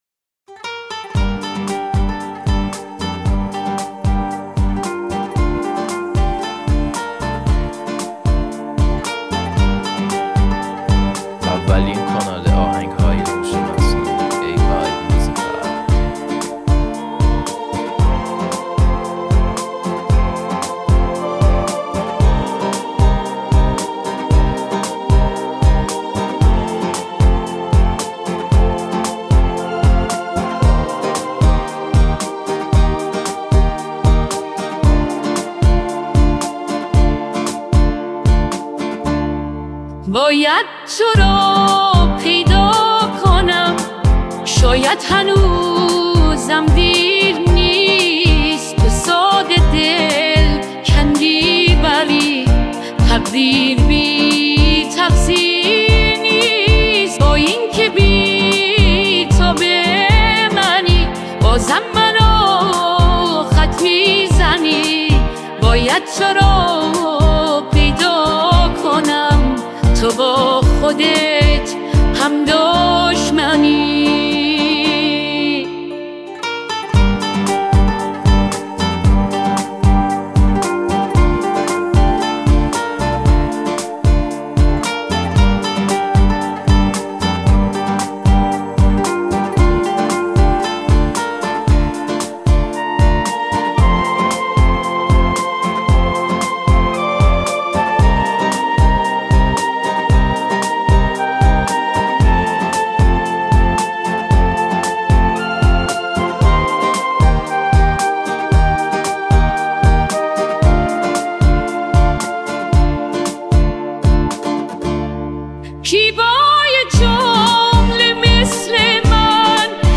Sakhte Hoshemasnoei